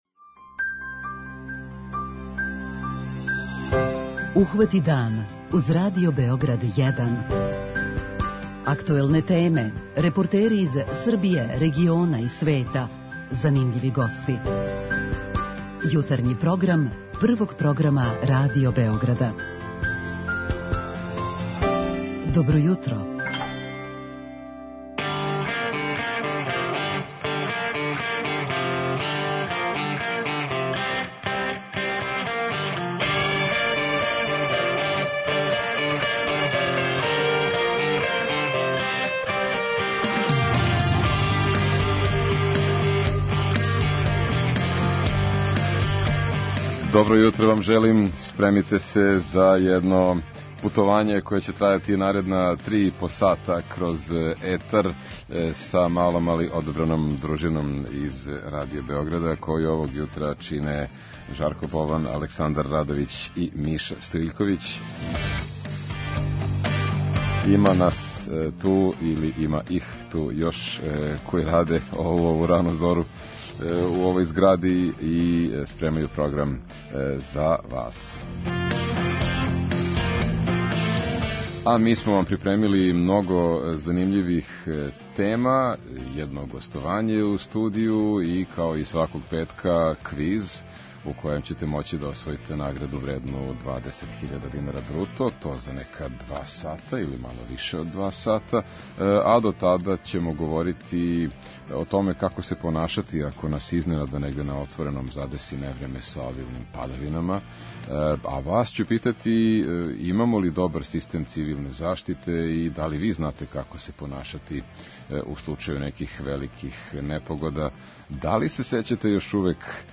О овој теми разговараћемо са слушаоцима у 'Питању јутра', а чућемо и савете стручњака из Горске службе спасавања.